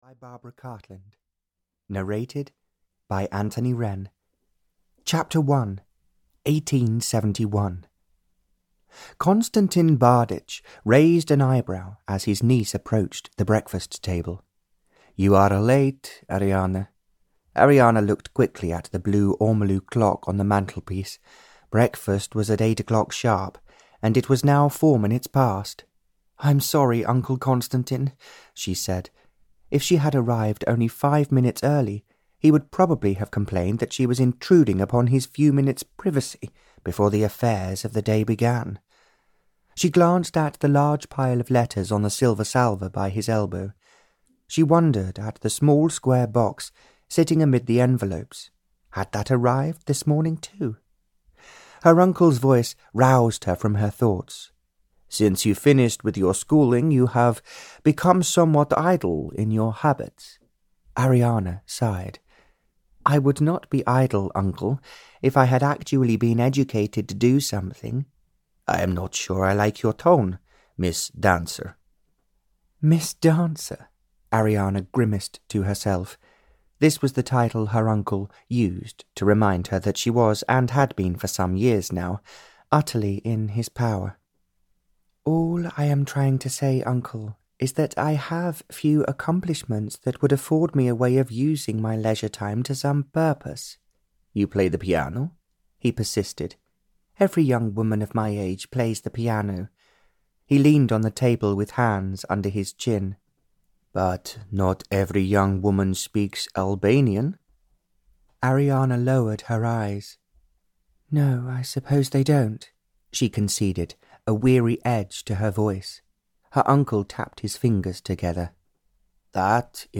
Double The Love (EN) audiokniha
Ukázka z knihy